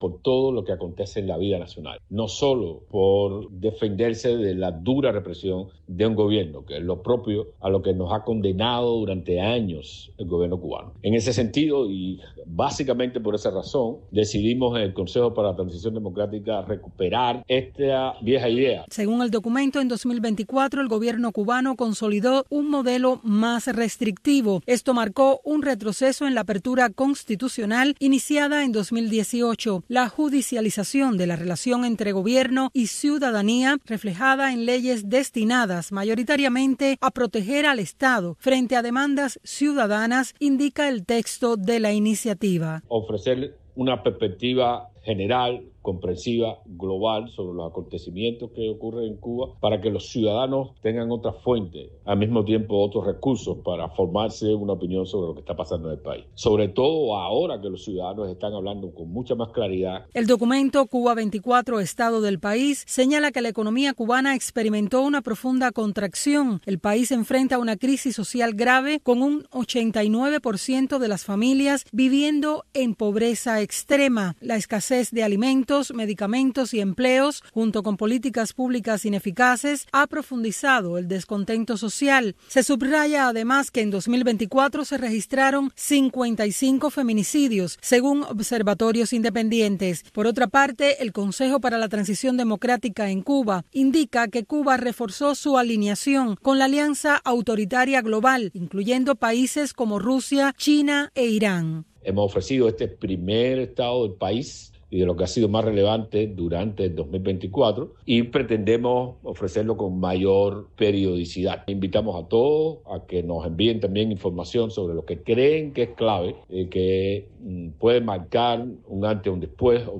Un espacio radial que va más allá de los nuevos avances de la ciencia y la tecnología, pensado para los jóvenes dentro de la isla que emplean las nuevas tecnologías para dar solución a sus necesidades cotidianas.